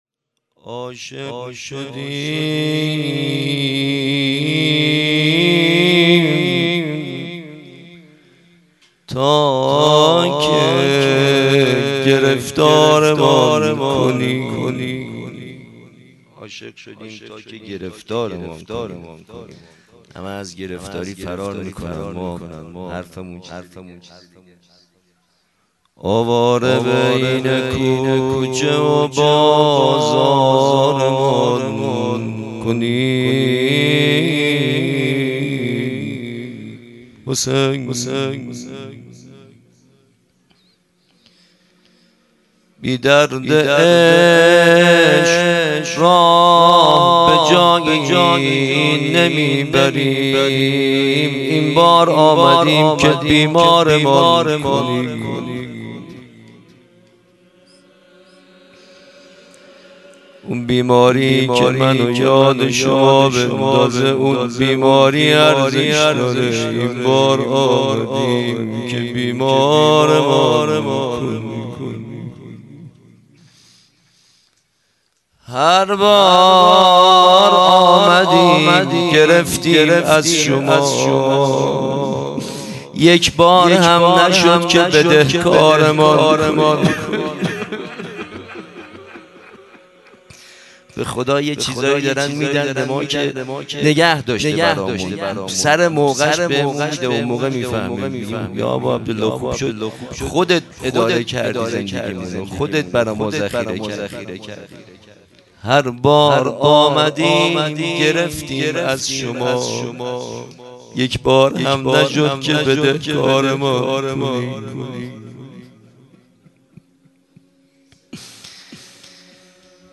صوت جلسه هیأت
روضه-کم-حجم.mp3